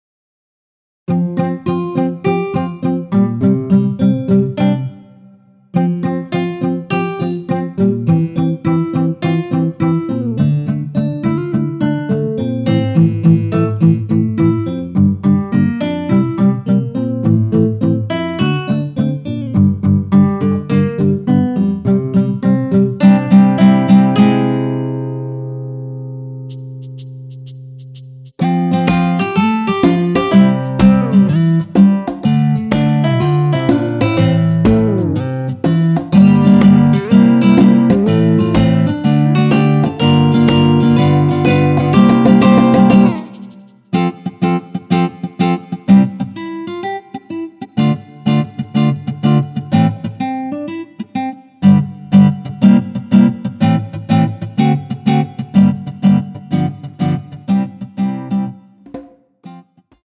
전주 없이 시작 하는곡이라 노래 하시기 편하게 전주 2마디 많들어 놓았습니다.(미리듣기 확인)
원키에서(+1)올린 MR입니다.
앞부분30초, 뒷부분30초씩 편집해서 올려 드리고 있습니다.
중간에 음이 끈어지고 다시 나오는 이유는